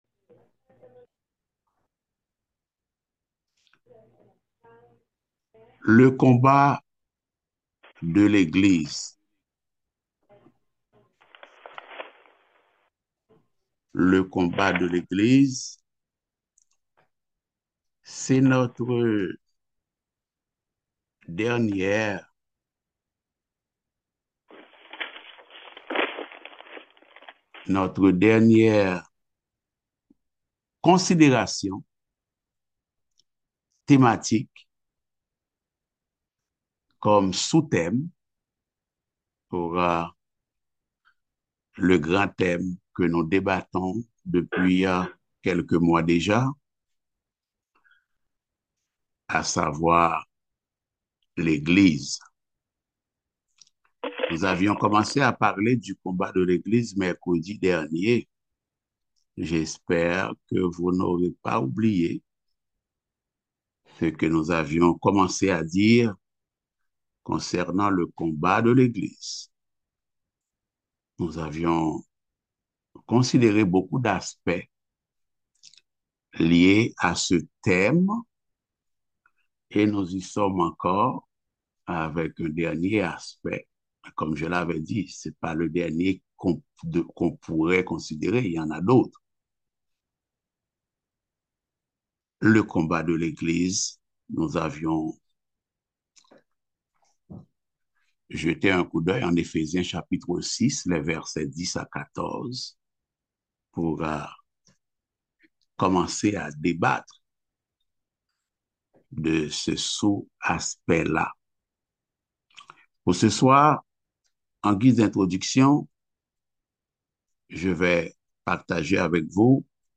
Passage: Éphésiens 6.10-27 Type De Service: Études Bibliques « La souveraineté de Dieu dans l’incarnation de son Fils